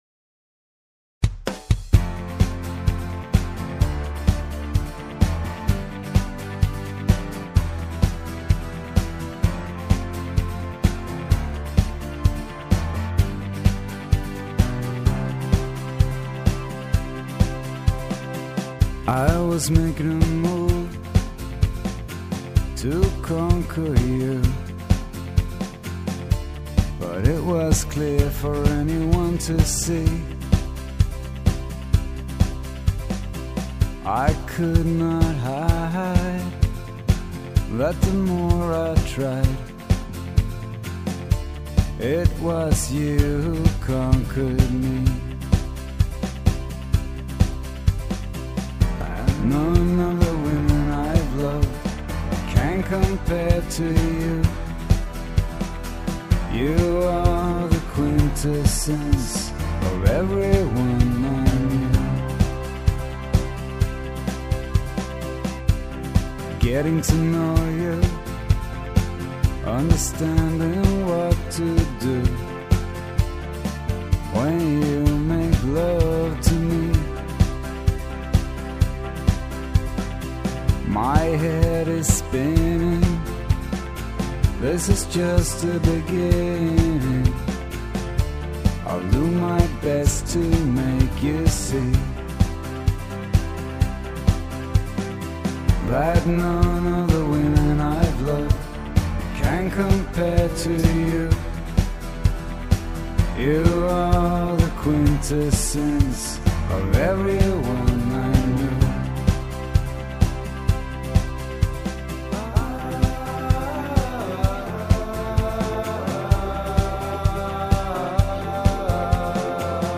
(Pop)